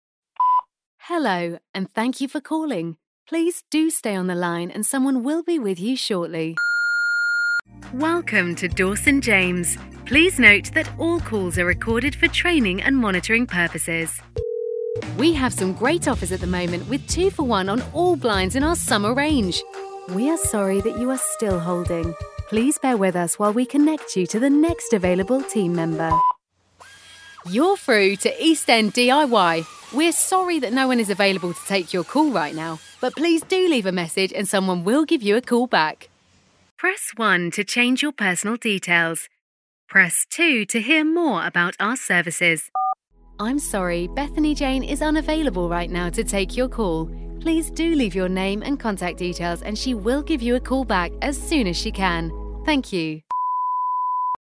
Inglés (Británico)
Comercial, Cool, Versátil, Cálida
Telefonía